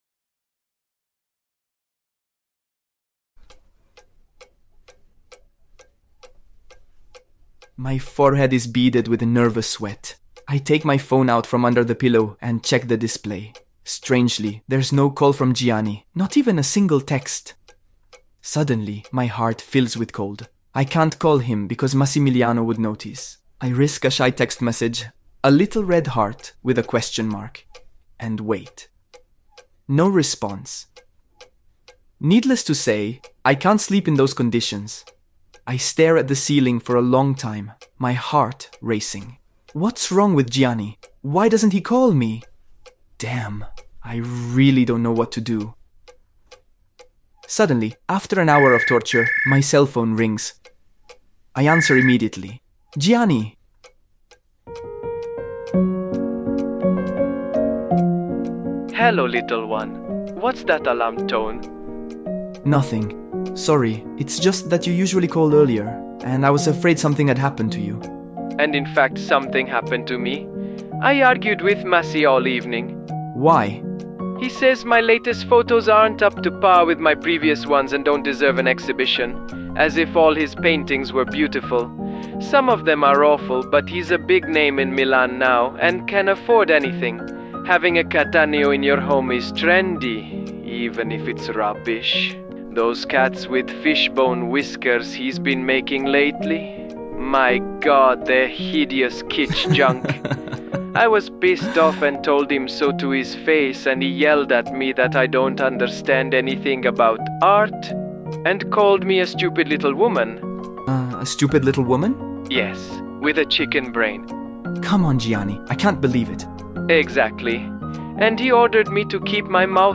The soundtrack includes "Valzer d'inverno" by Andrea Vanzo.